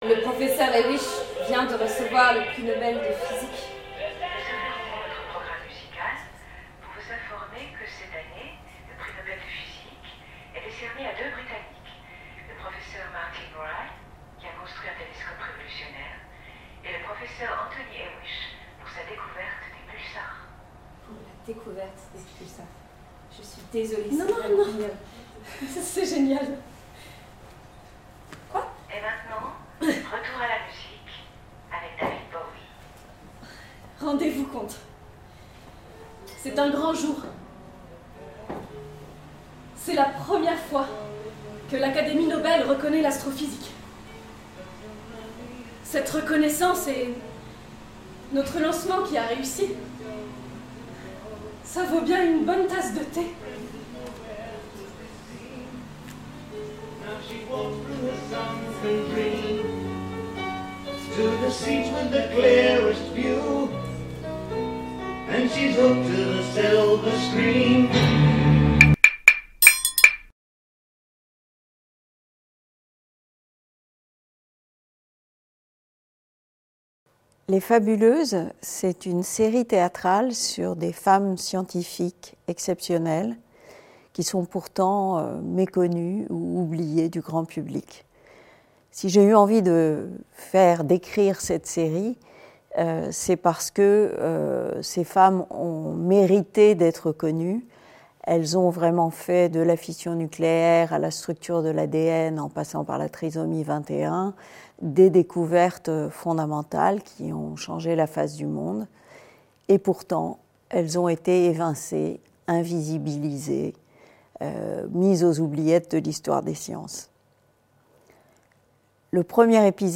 Théâtre scientifique